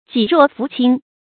济弱扶倾 jì ruò fú qīng
济弱扶倾发音
成语注音 ㄐㄧˋ ㄖㄨㄛˋ ㄈㄨˊ ㄑㄧㄥ